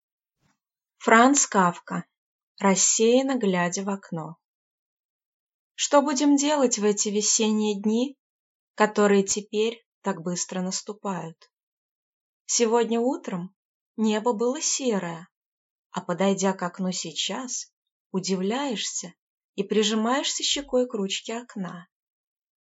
Аудиокнига Рассеянно глядя в окно | Библиотека аудиокниг